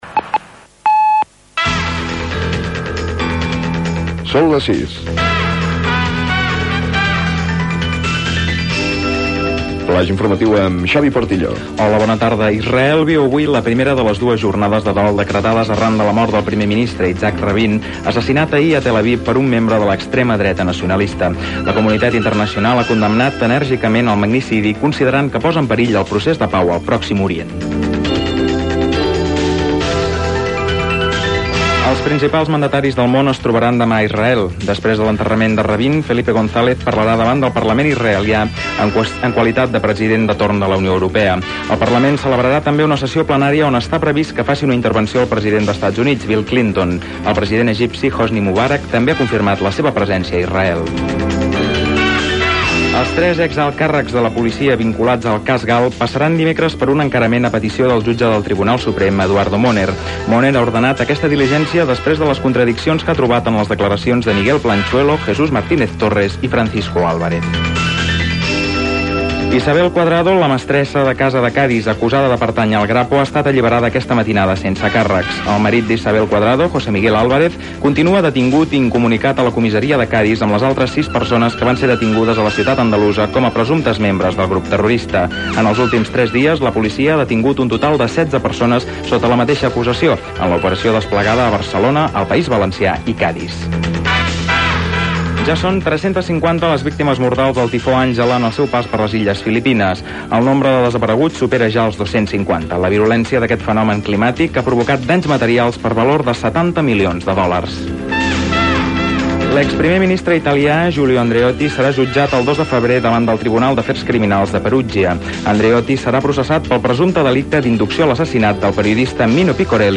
Hora: butlletí horari: assassinat d'Isaac Rabin, trobada de presidents de diversos països a Israel, cas GAL, detinguts membres d'ETA, tifó Àngela a Filipines, etc. Indicatiu i hora.
Informatiu